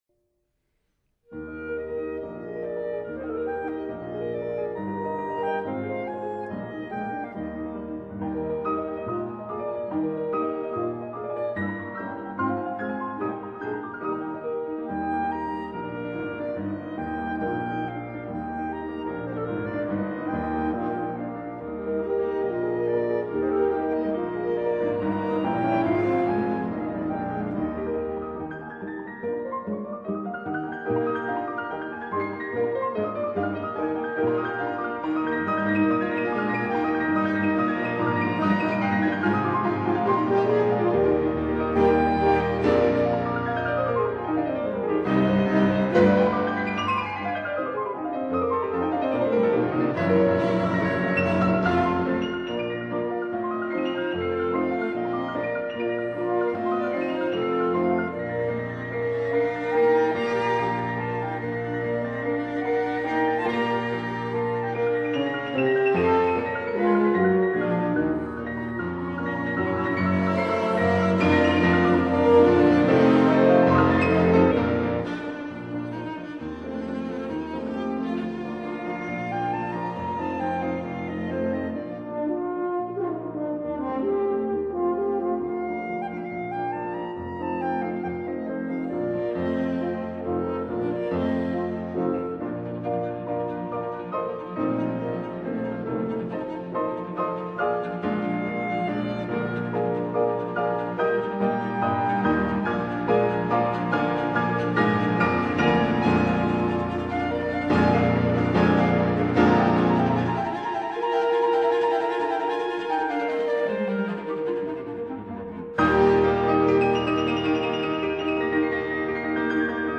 Allegro vivace con fuoco    [0:04:41.32]